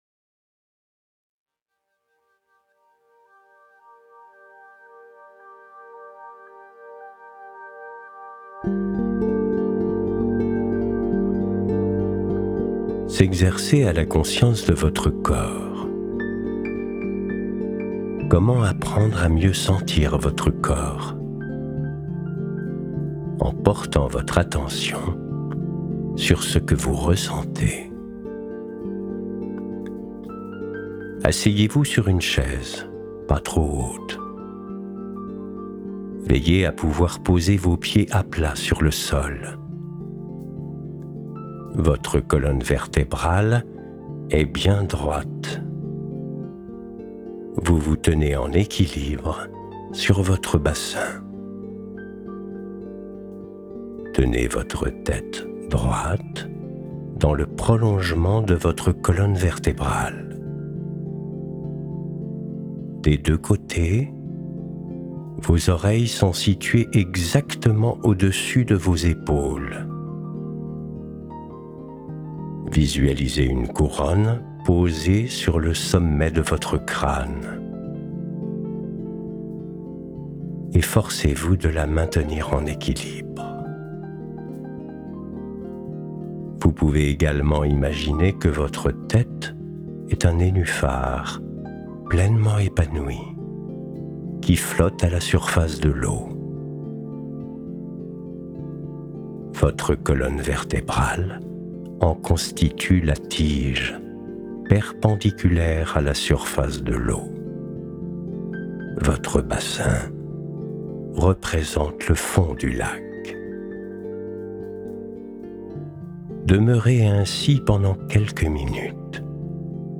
meditation-conscience-du-corps.mp3